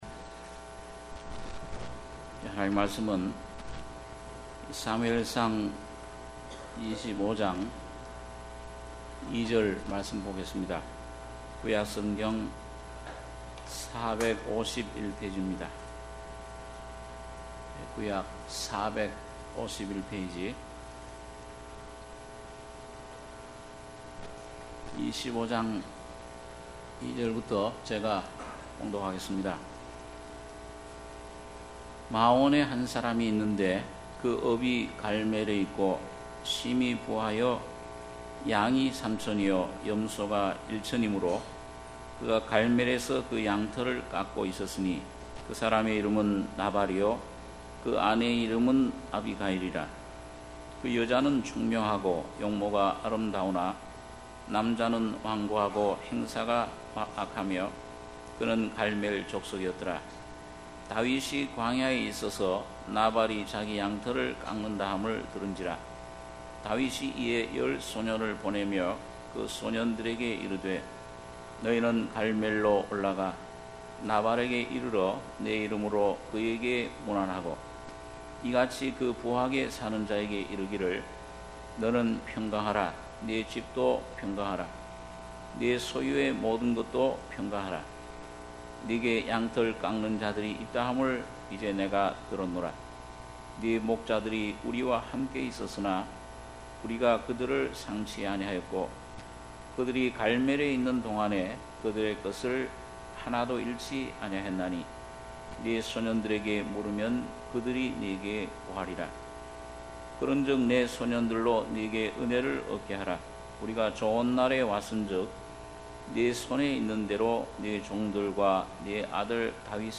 주일예배 - 사무엘상 25장 2-13절, 23-31절